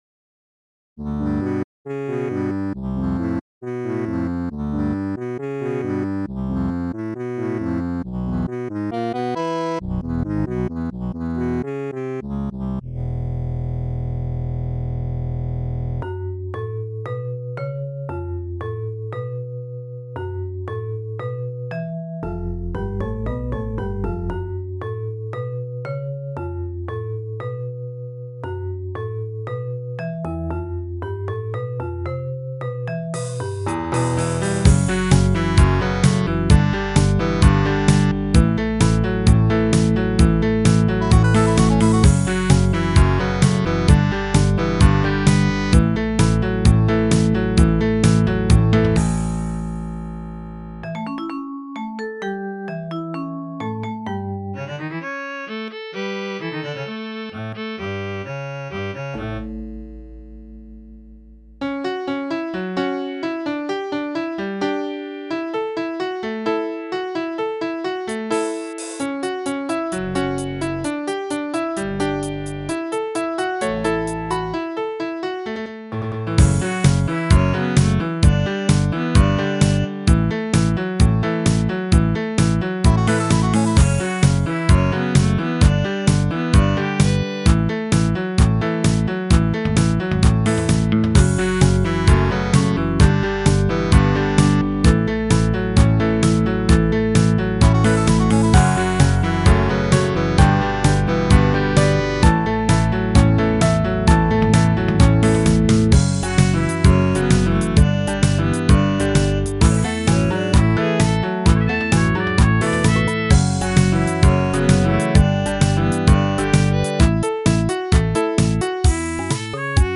ASTRAL ELECTRO MUSIC ; POP MUSIC